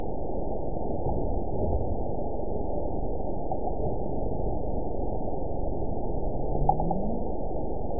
event 912531 date 03/28/22 time 21:09:48 GMT (3 years, 1 month ago) score 9.49 location TSS-AB05 detected by nrw target species NRW annotations +NRW Spectrogram: Frequency (kHz) vs. Time (s) audio not available .wav